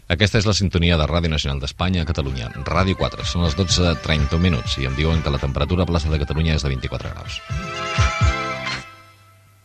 Identificació de l'emissora i temperatura a Plaça Catalunya de Barcelona